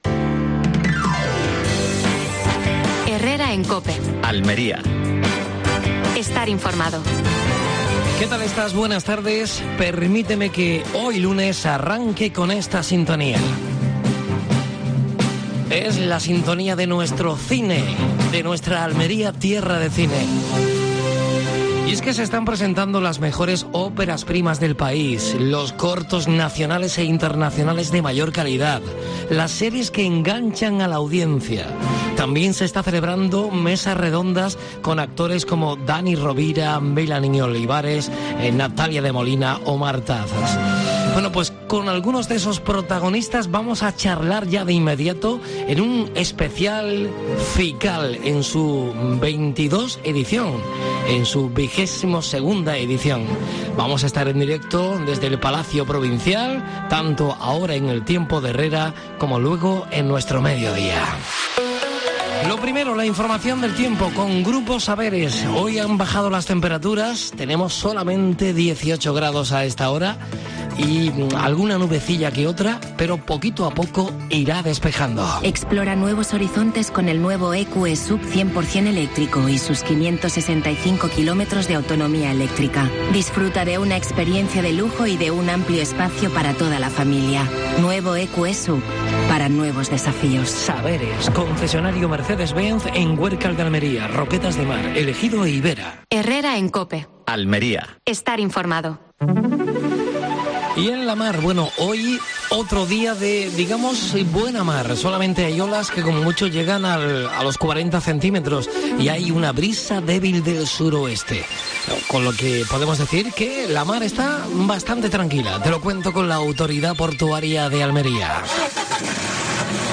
AUDIO: Última hora en Almería. FICAL 2023: entrevista a Marta Hazas (actriz).